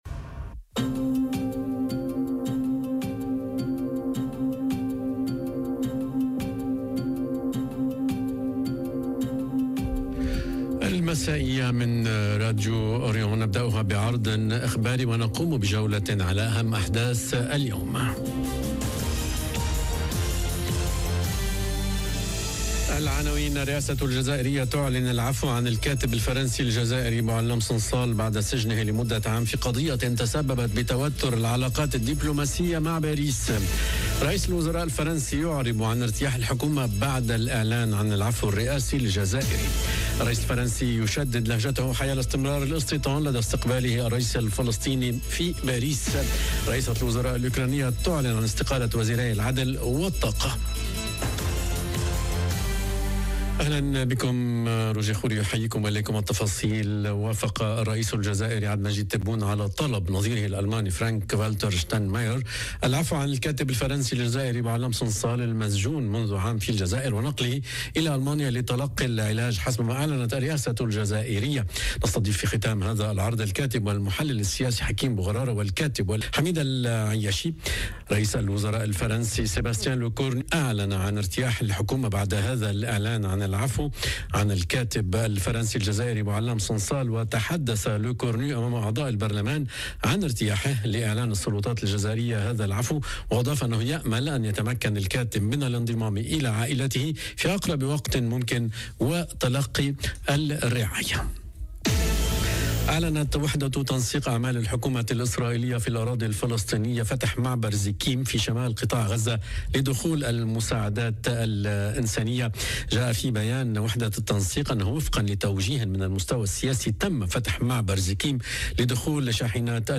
نشرة أخبار المساء: الرئاسة الجزائرية تعلن العفو عن الكاتب الفرنسي الجزائري بوعلام صنصال بعد سجنه لمدة عام في قضية تسببت بتوتر العلاقات الدبلوماسية مع باريس....